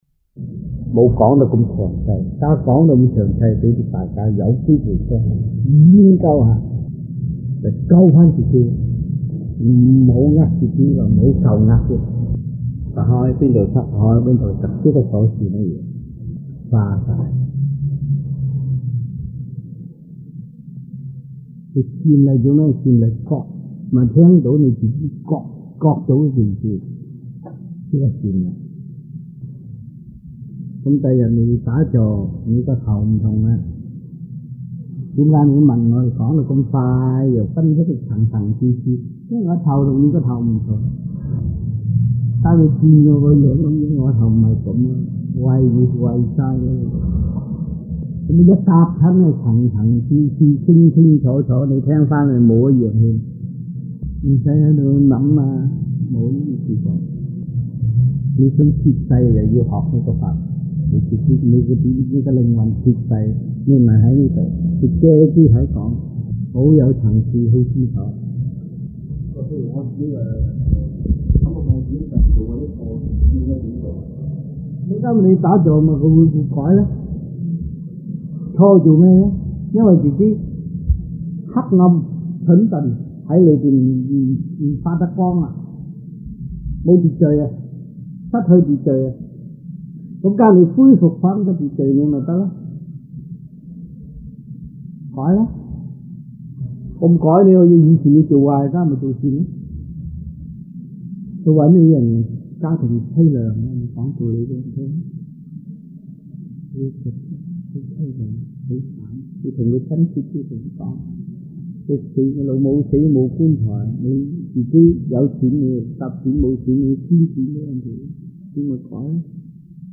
Lectures-Chinese-1988 (中文講座)